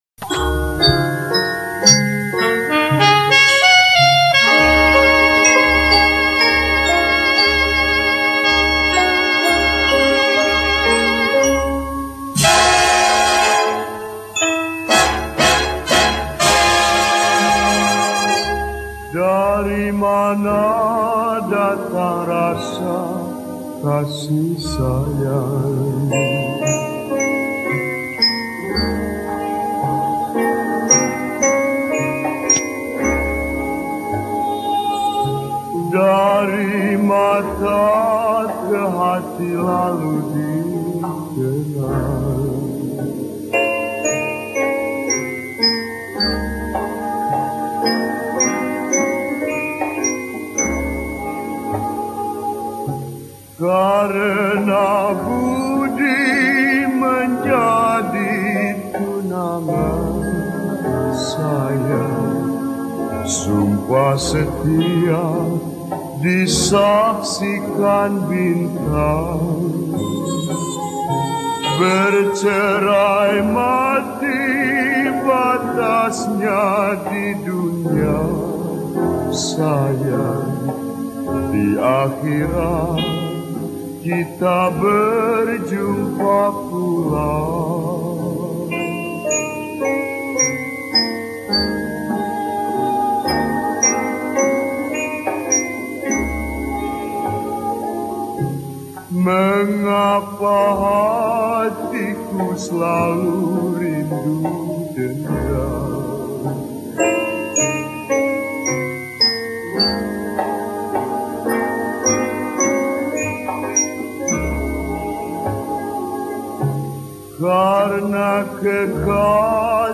Malay Songs